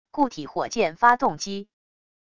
固体火箭发动机wav音频